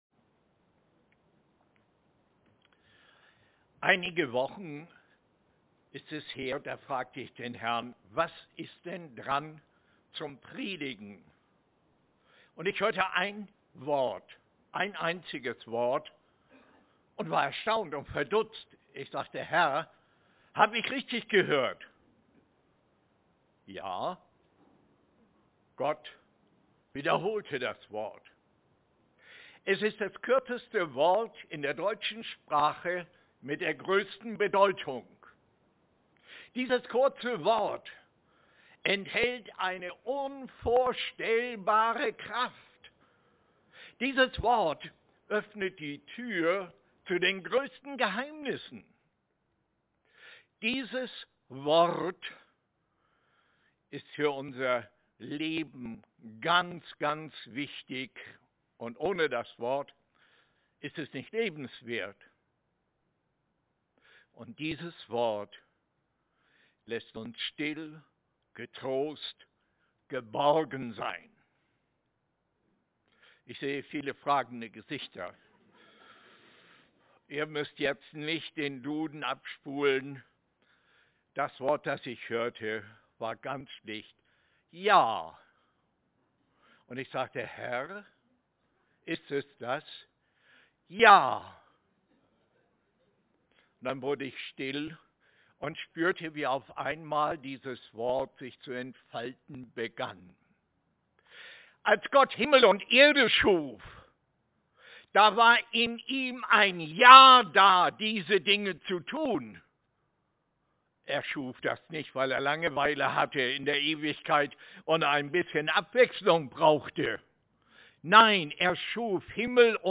Eine allgemeine Predigt
Aktuelle Predigten aus unseren Gottesdiensten und Veranstaltungen